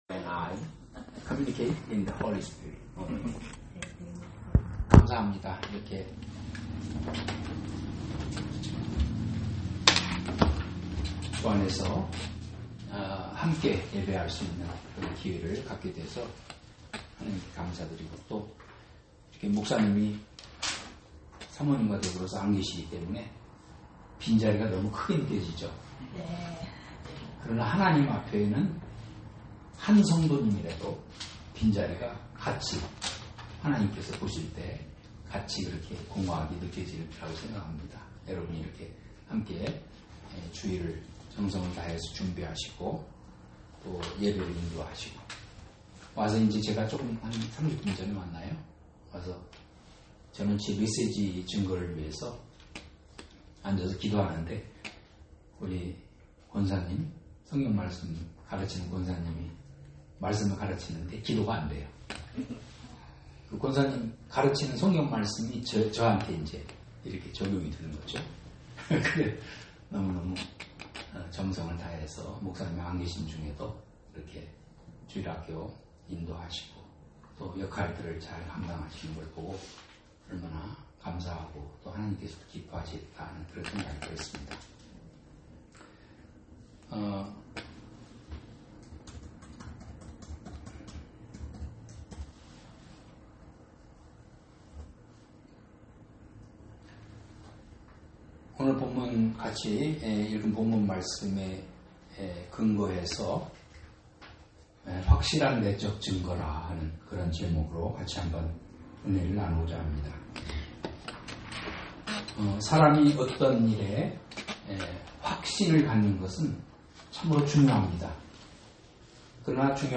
Sermons - 벧엘한인침례교회